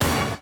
RAP ORCH HIT.wav